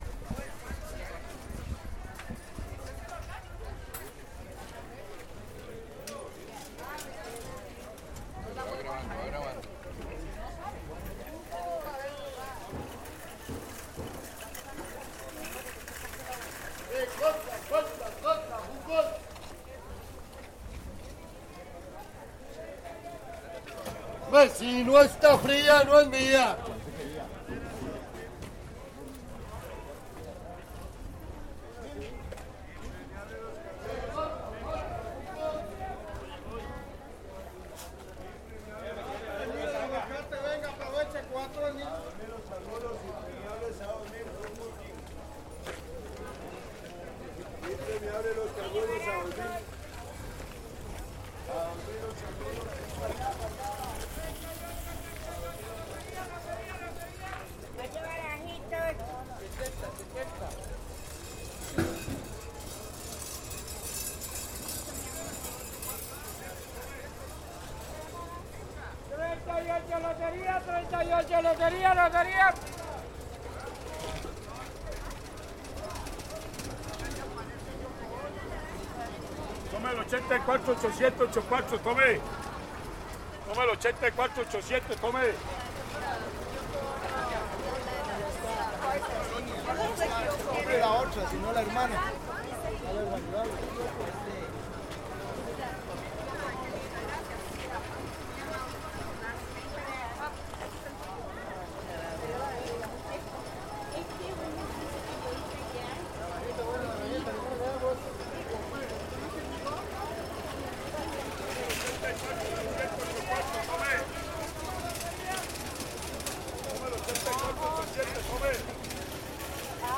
Caminata en Feria de Plaza Viquez
A pesar de eso, se escuchan ventas, acentos y dichos habituales de la feria.
Caminata en Feria de Plaza Viquez ACM 2018.mp3